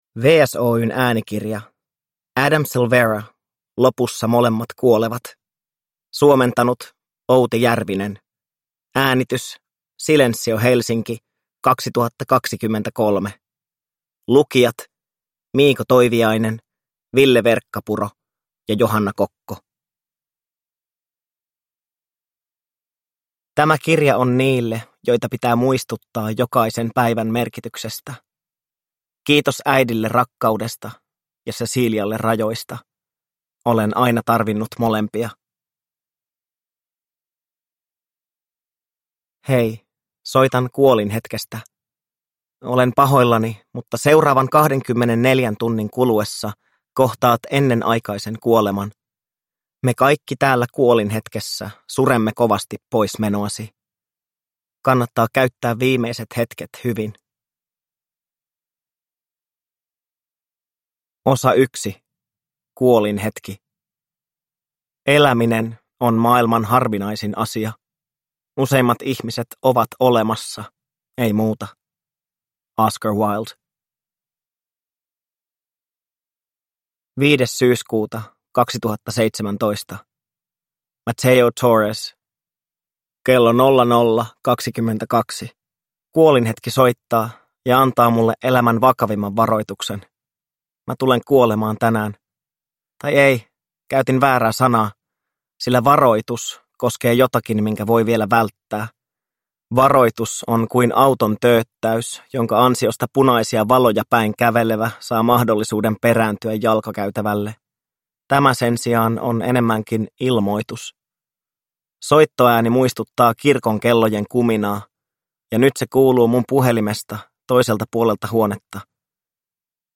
Lopussa molemmat kuolevat – Ljudbok – Laddas ner